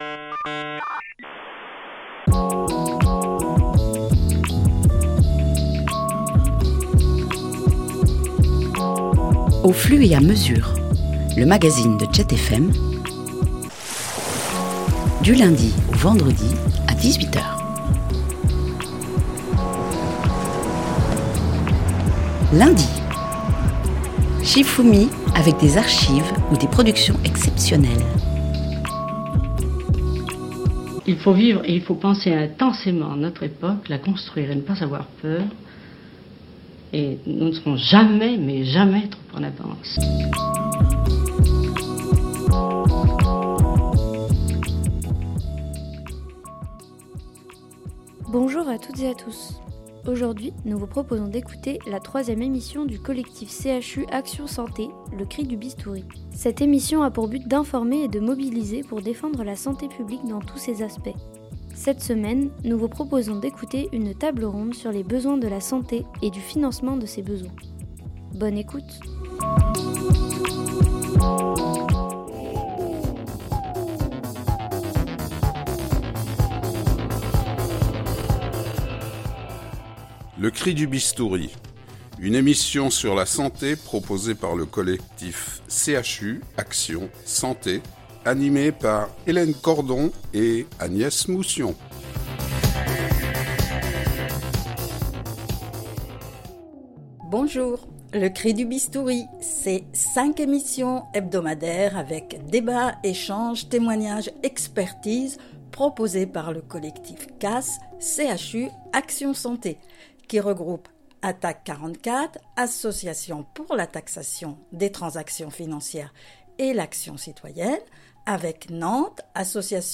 L’émission santé du lundi 7 mars porte sur les besoins, enjeux et financements de la santé publique lors d’une table ronde avec le collectif nantais santé